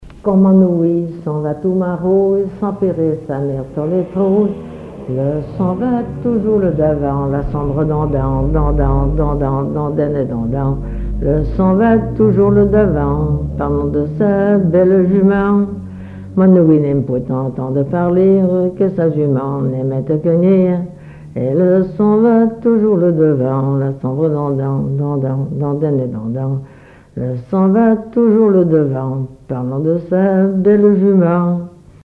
Témoignages et chansons traditionnelles
Pièce musicale inédite